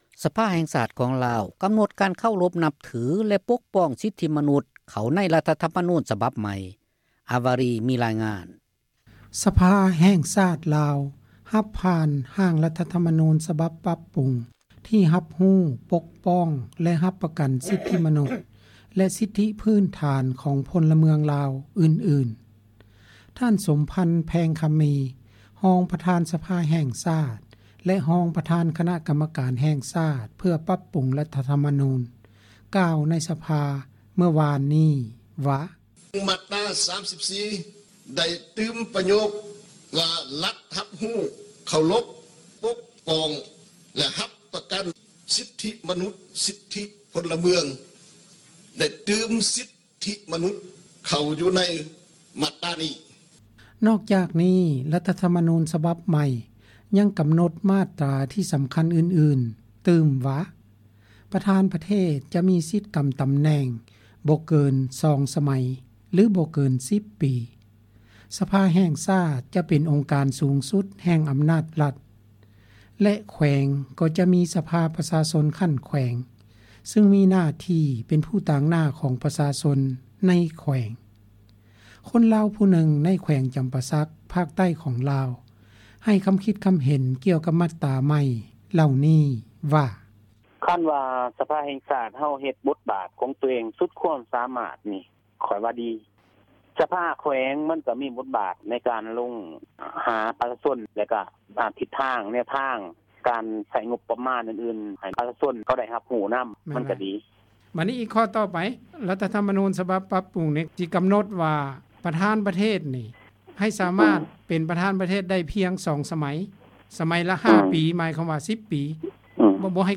ສະພາເພີ້ມສິດທິມະນຸດໃສ່ ມາດຕຣາ 34 – ຂ່າວລາວ ວິທຍຸເອເຊັຽເສຣີ ພາສາລາວ